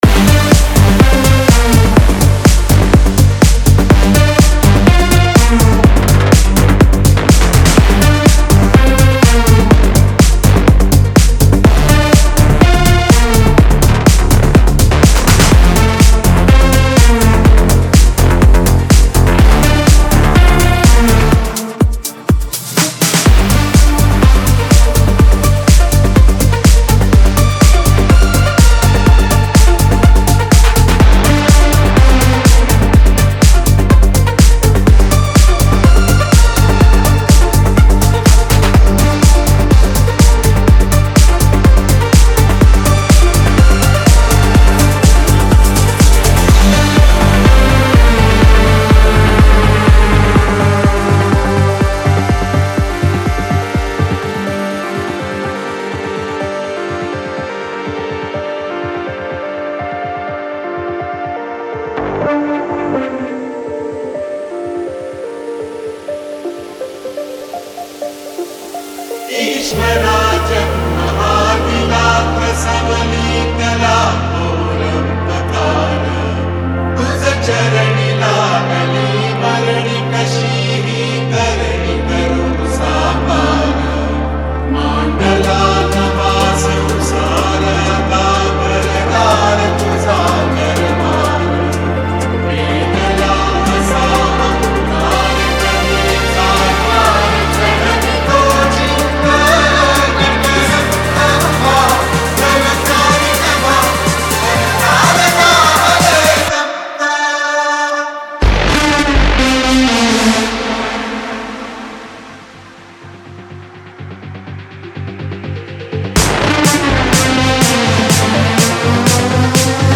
#1 New Marathi Dj Song Album Latest Remix Releases